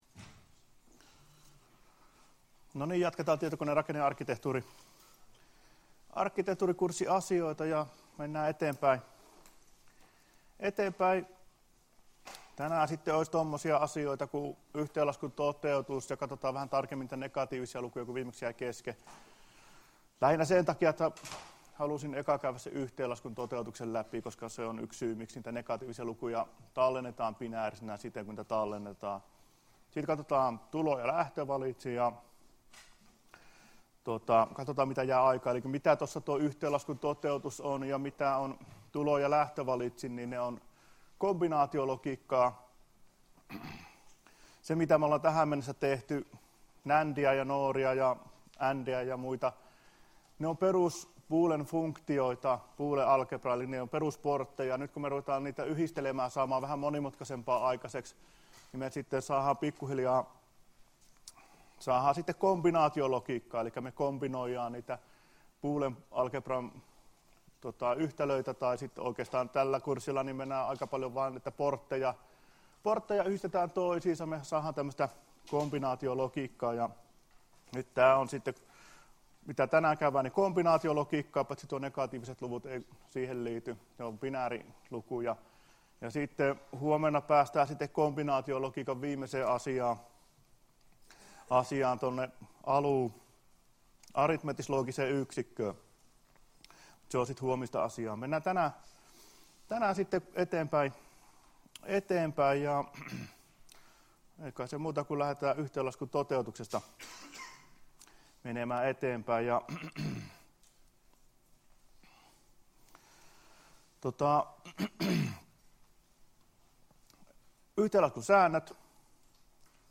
Luento 27.9.2016 — Moniviestin